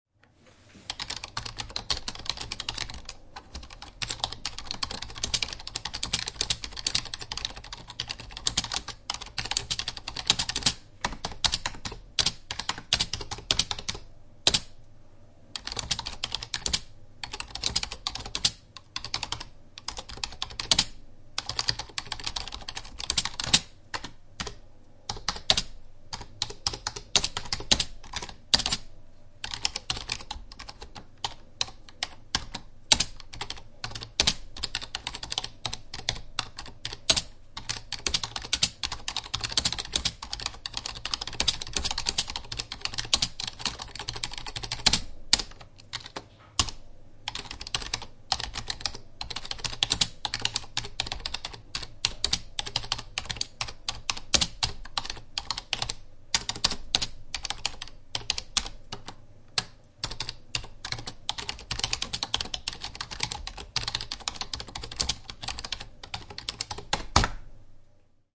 Download Keyboard sound effect for free.
Keyboard